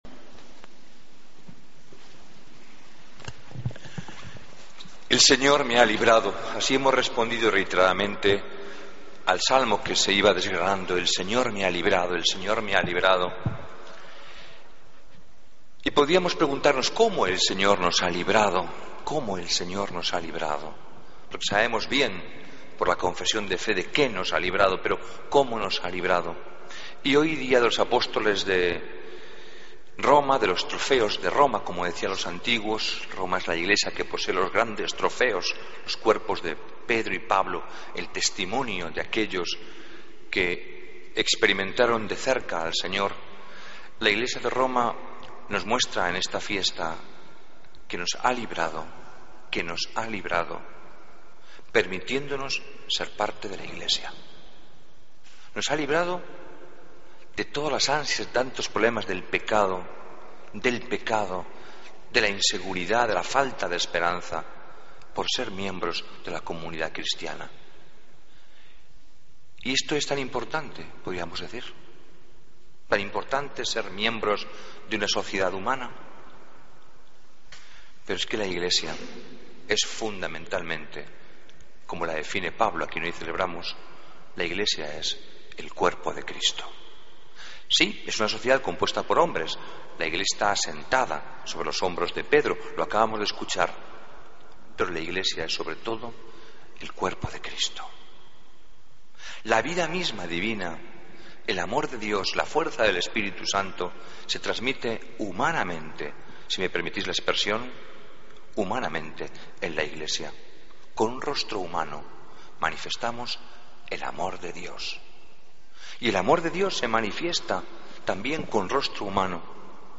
Homilía del Domingo 29 de Junio de 2014